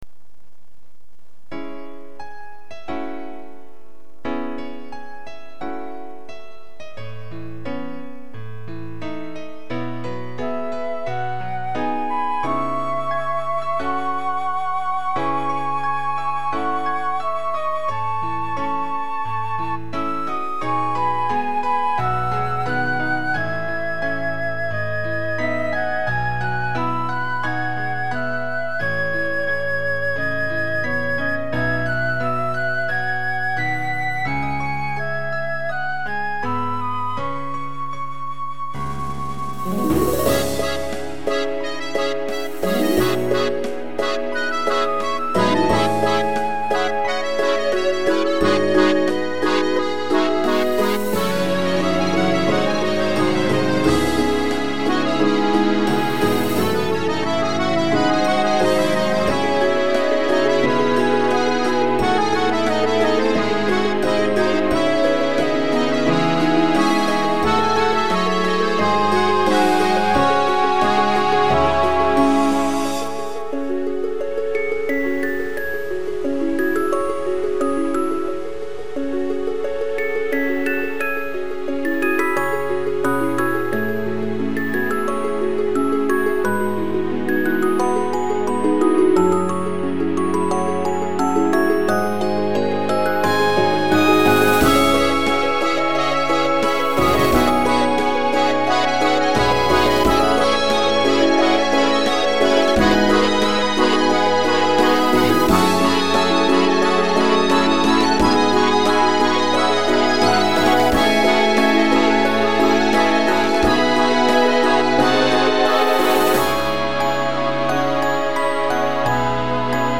大音量推奨。